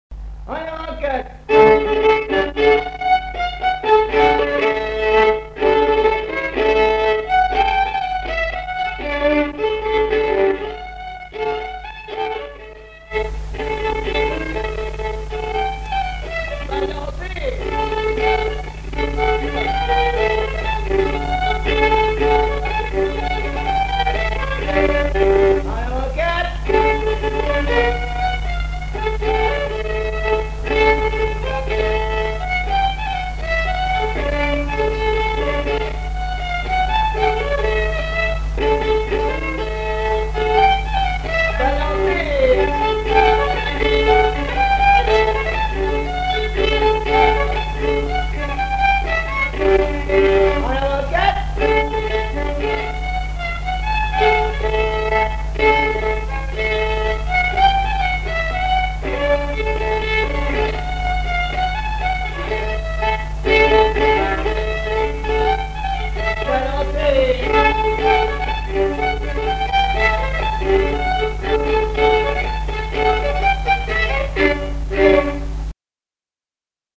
Chants brefs - A danser
quadrille : avant-quatre
Répertoire de violoneux
Pièce musicale inédite